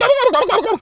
turkey_ostrich_hurt_gobble_04.wav